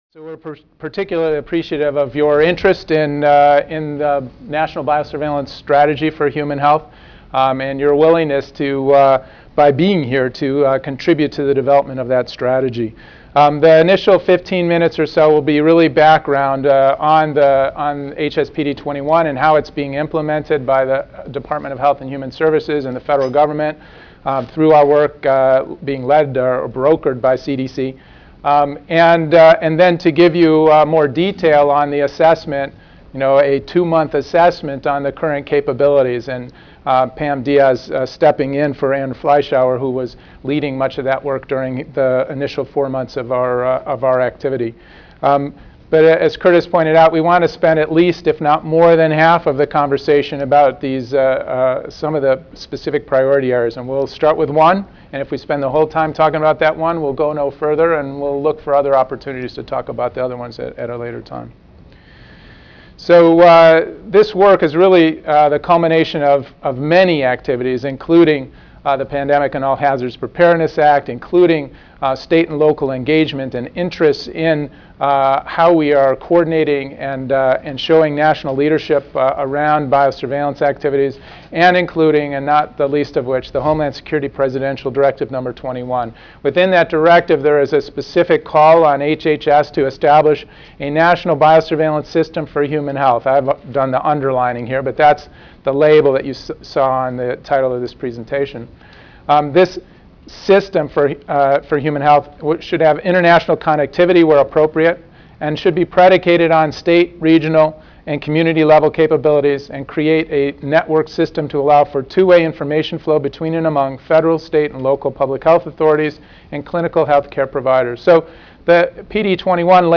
CDC Audio File Slides Recorded presentation